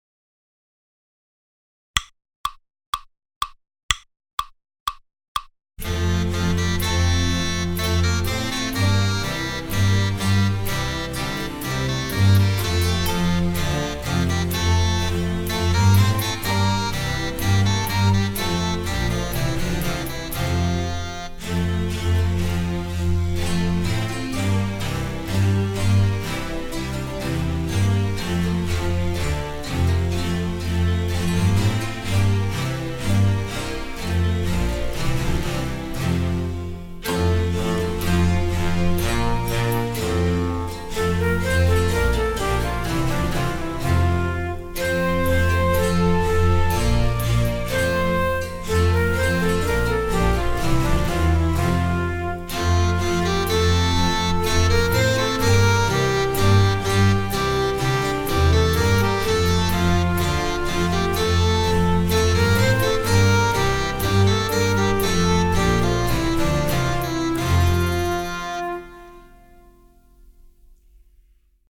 Proposta B: Audio velocidade orixinal sen frauta